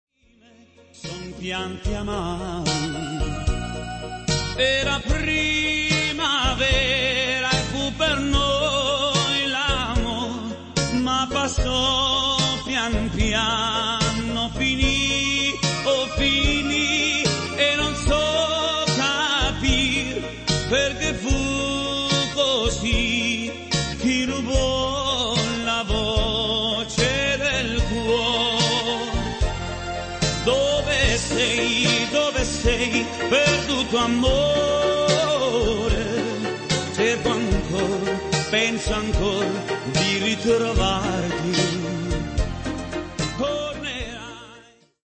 terzinato